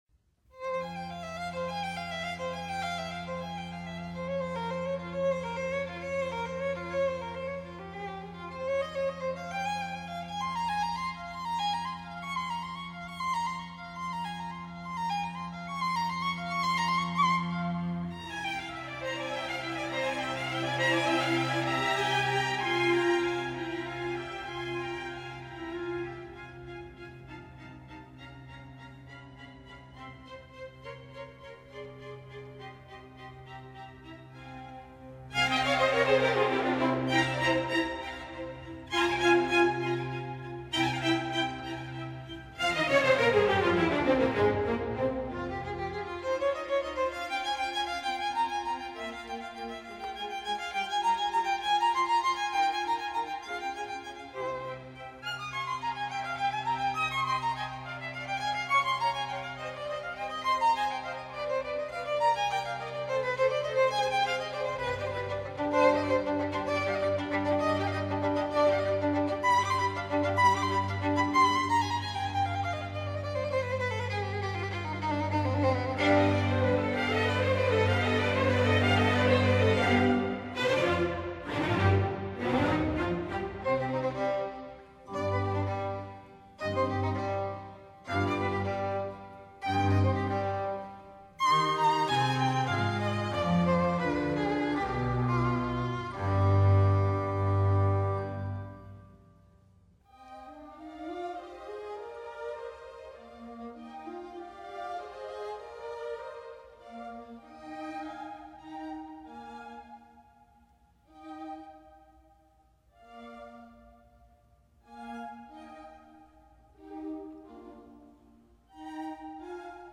末乐章的一长串滑行的乐旬开始。
全奏进入。